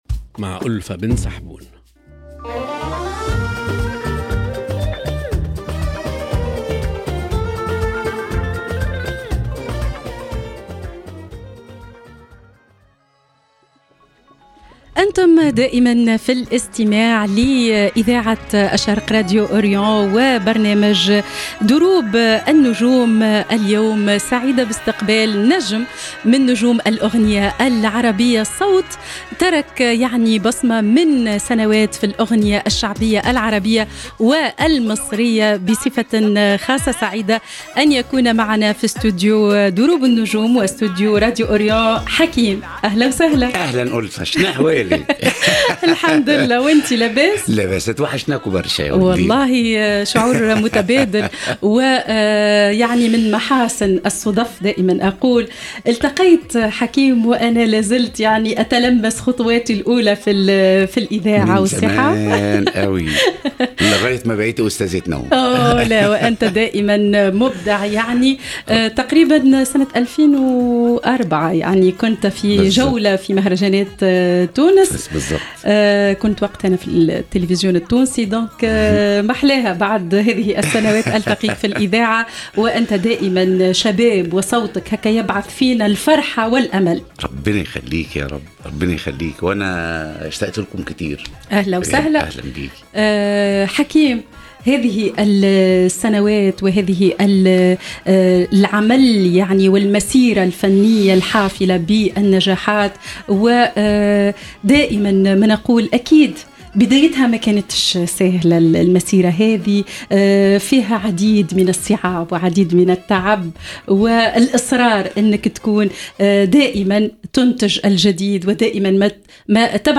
هو الفنان المصري الكبير حكيم ، الذي تربّع على قلوب الجماهير في مصر والعالم العربي بفضل حضوره الطاغي وأدائه المفعم بالحيوية، وجعل من كل أغنيةٍ قصةً تعبّر عن روح الحياة المصرية. في هذا اللقاء، نرافق حكيم في رحلةٍ عبر محطّاته الفنية ، لنتعرّف على بداياته وأسرار نجاحه ، وكيف استطاع أن يحافظ على مكانته في عالم الفن، رغم تغيّر الأذواق وتنوّع المدارس الغنائية.
الحوار